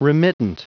Prononciation du mot remittent en anglais (fichier audio)
Prononciation du mot : remittent